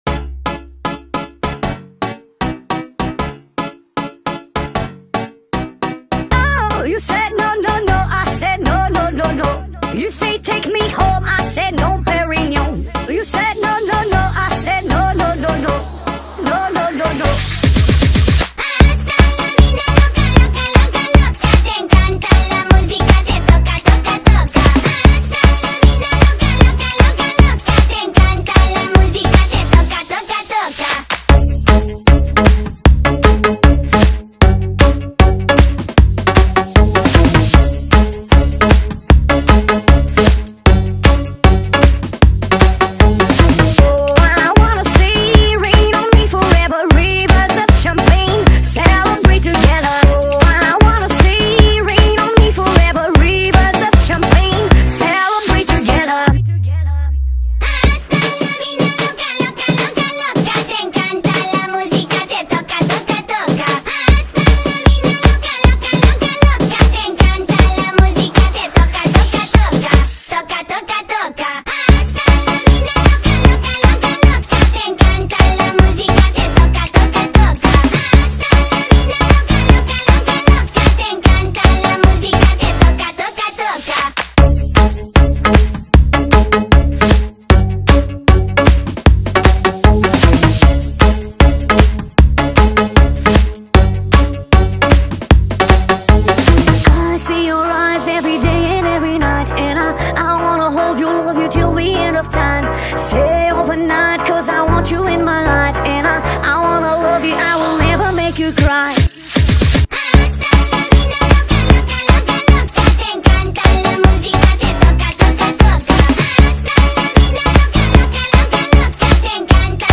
با ریتمی سریع شده
شاد